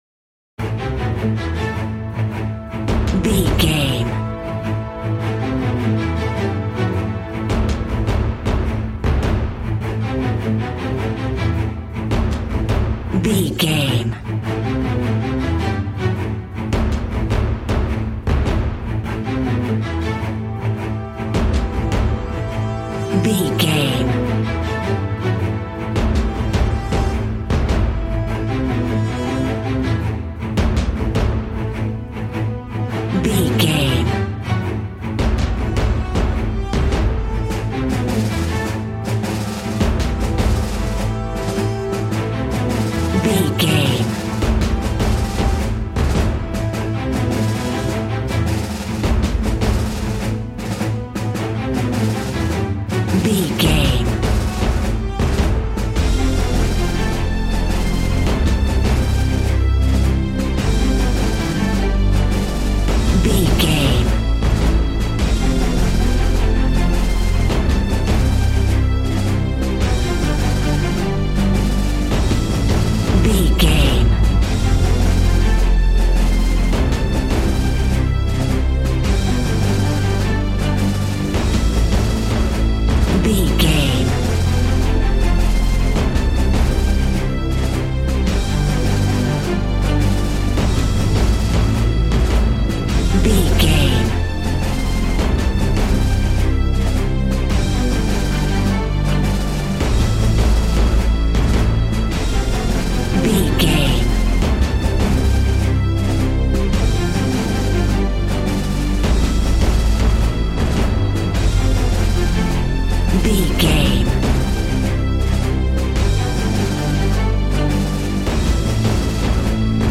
Aeolian/Minor
strings
percussion
synthesiser
brass
violin
cello
double bass